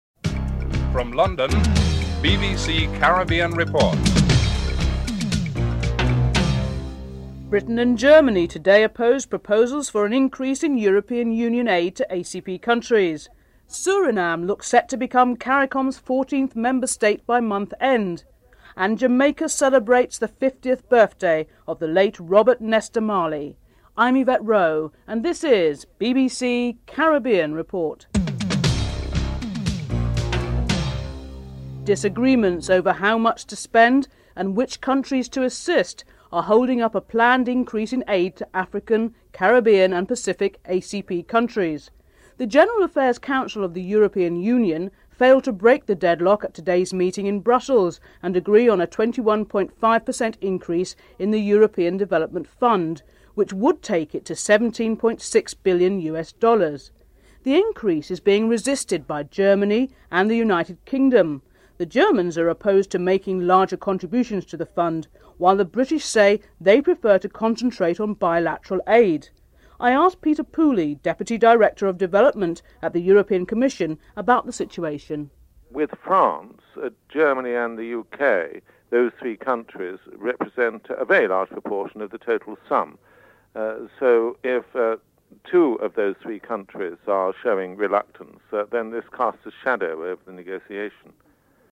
7. Report ends with the music of Bob Marley (14:13-14:50)